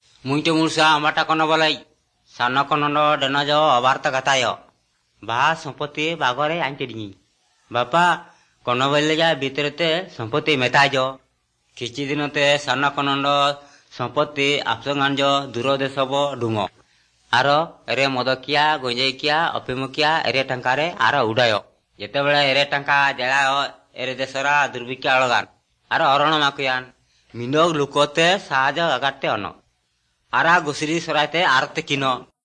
Here’s a song in multiple languages.